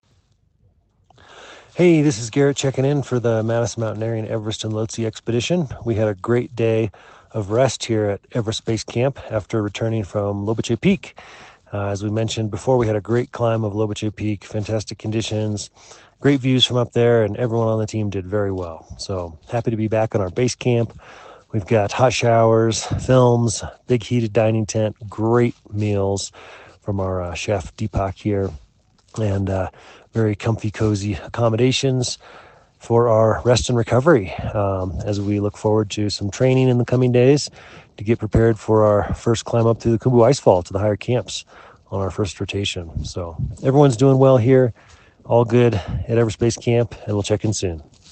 checks in with this dispatch from Everest Base Camp: